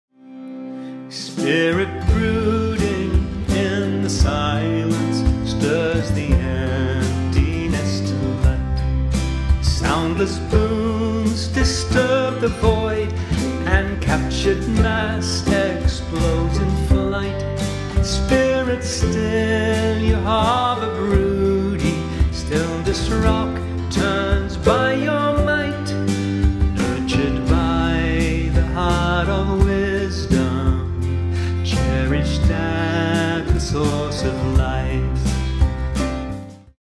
and feature vocals by a range of SA singers